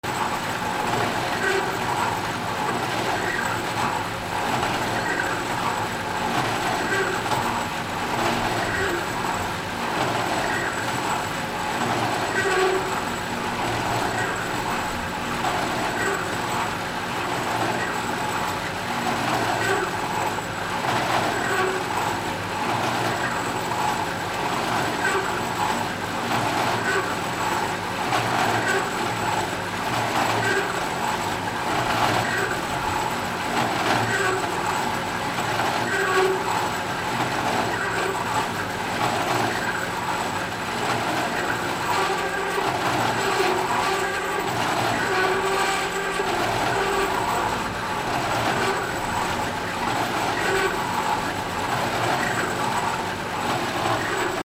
/ M｜他分類 / L10 ｜電化製品・機械
掘削機